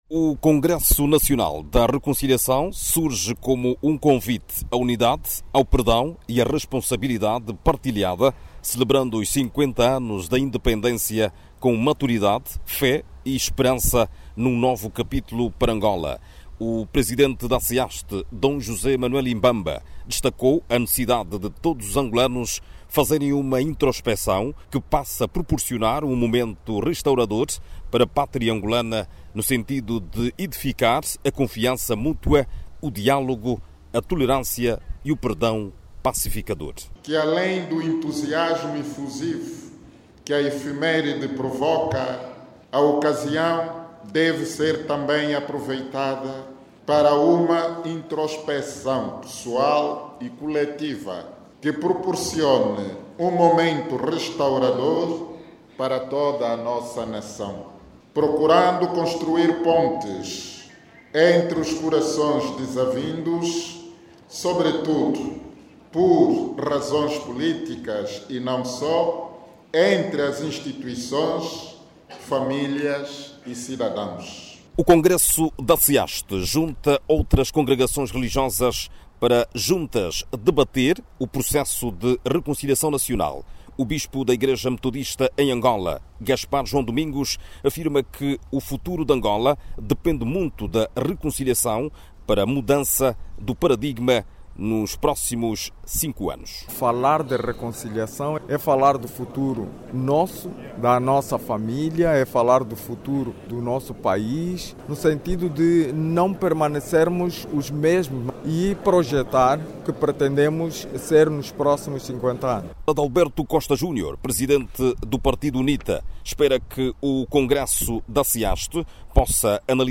A CEAST, diz que o Congresso, é mais um momento de construção dos valores autênticos da reconciliação nacional. Ouça no áudio abaixo toda informação com a reportagem